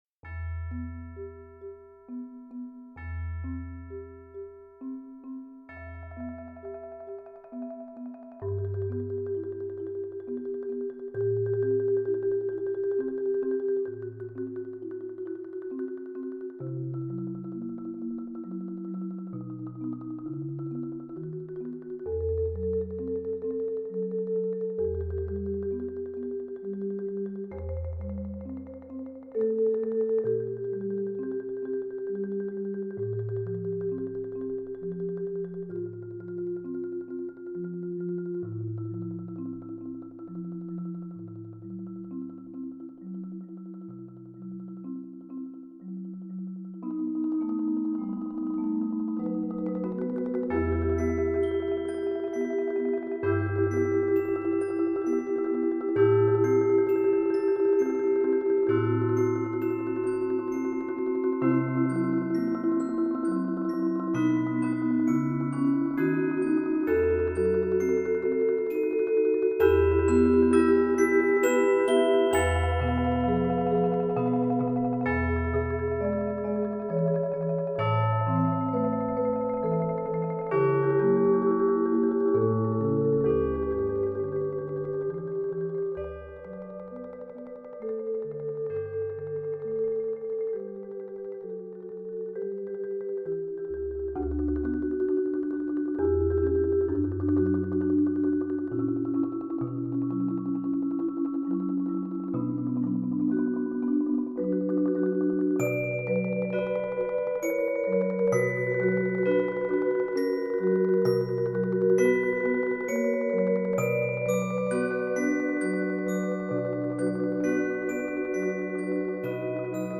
Genre: Percussion Ensemble
# of Players: 5-6
Bells
Vibraphone
Chimes [optional]
Marimba 1 [4-octave]*
Marimba 2 [4-octave]
Marimba 3 [5-octave]*#